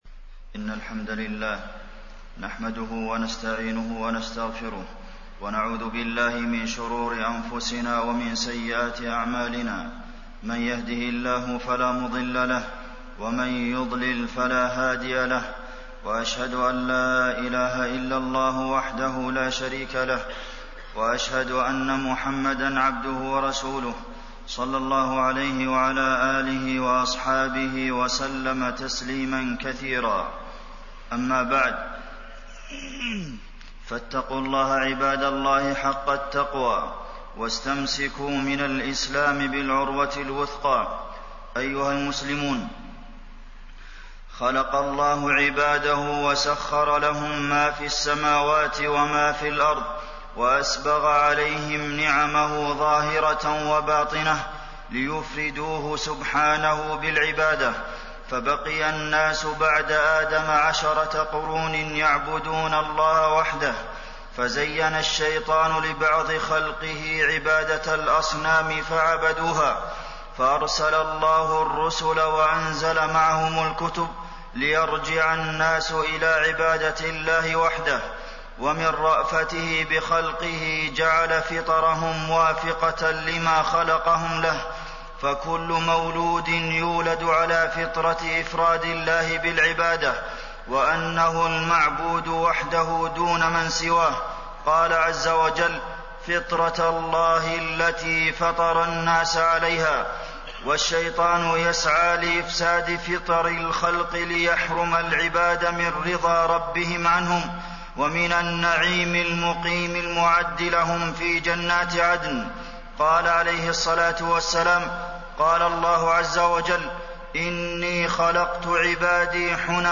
تاريخ النشر ٢٩ شوال ١٤٣١ هـ المكان: المسجد النبوي الشيخ: فضيلة الشيخ د. عبدالمحسن بن محمد القاسم فضيلة الشيخ د. عبدالمحسن بن محمد القاسم التوحيد وفضله